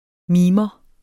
Udtale [ ˈmiːmʌ ]